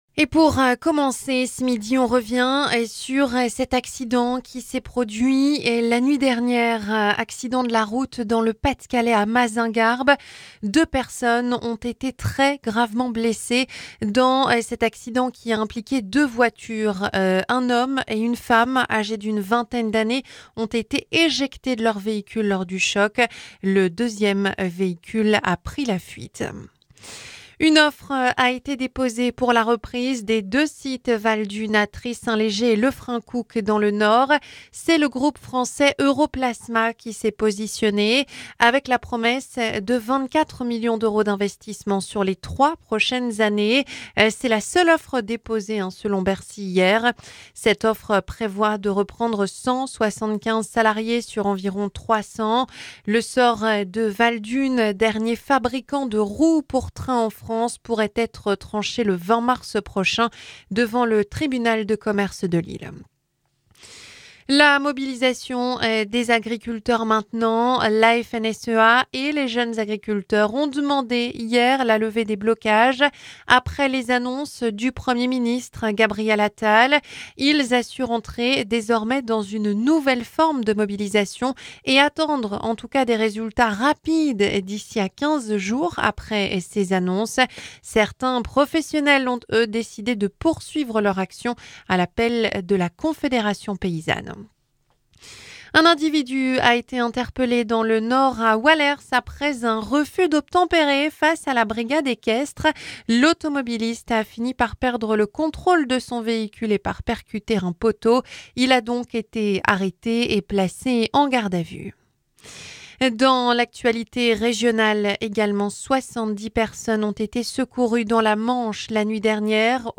Journal 12h - Une offre déposée pour Valdunes